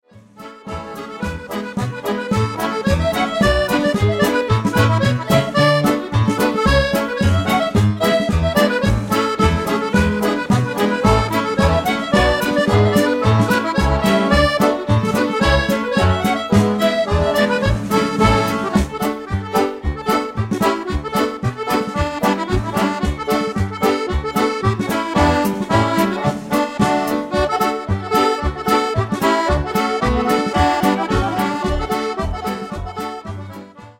8 x 32 Reel